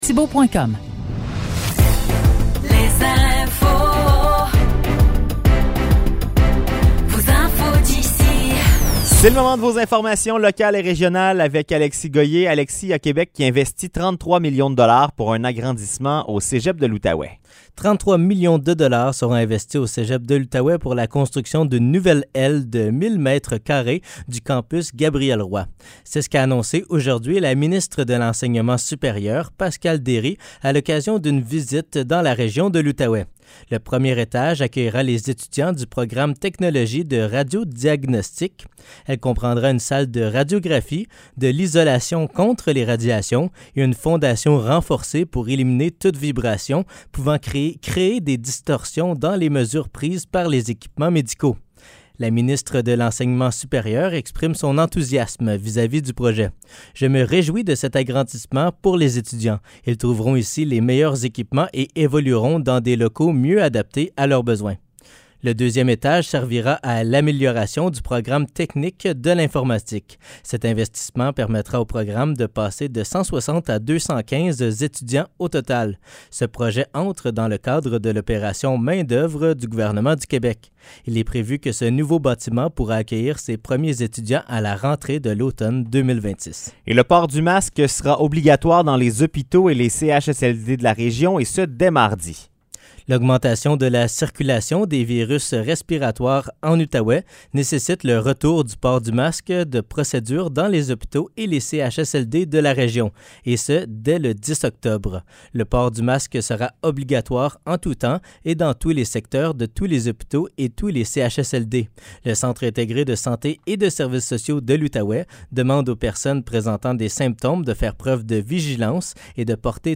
Nouvelles locales - 6 octobre 2023 - 16 h